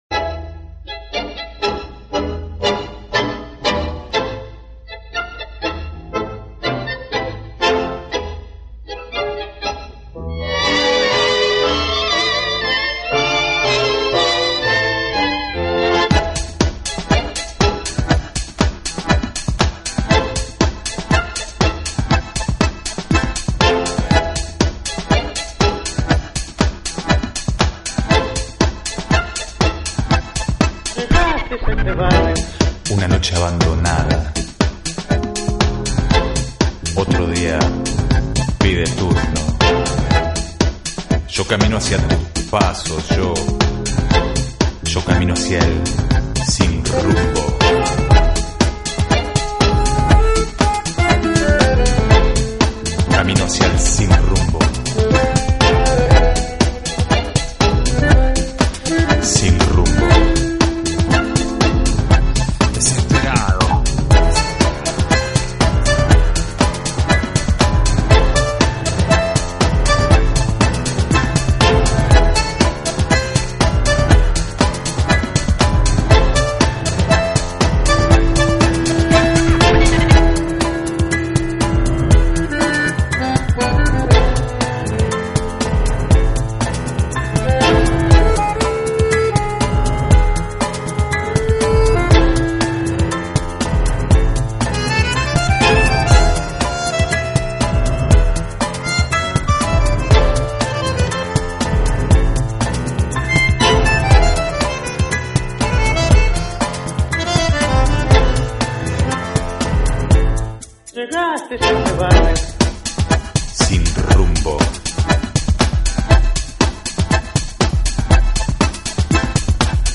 Genre: Lo-Fi / Lounge / Tango